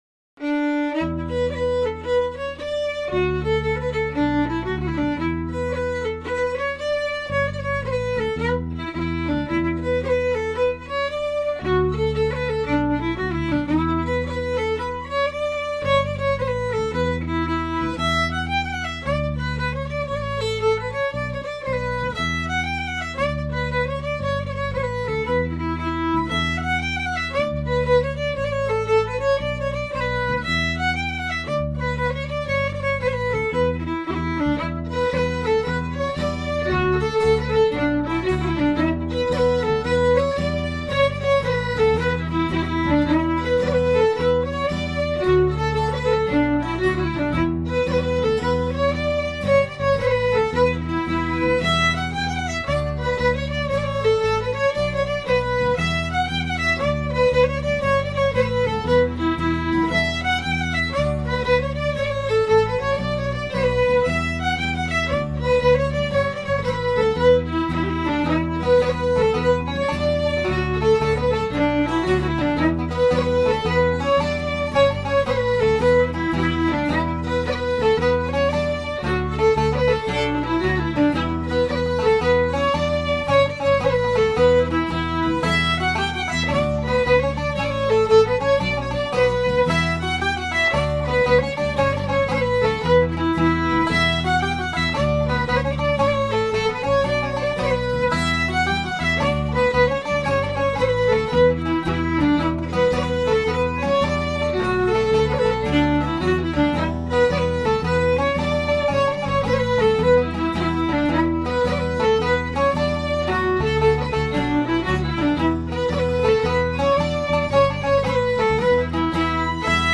The Road to Lisdoonvarna | Mandolin
Road to Lisdoonvarna Traditional (.mp3)